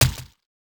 item_splat.wav